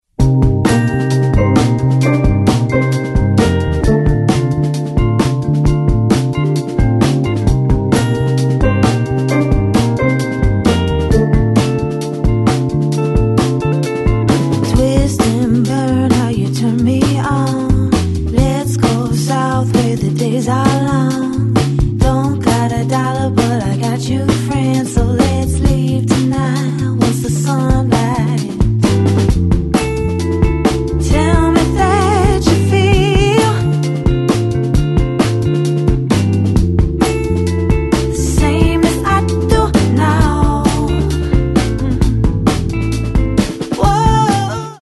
Genre : R&B/Soul